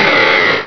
pokeemerald / sound / direct_sound_samples / cries / vigoroth.aif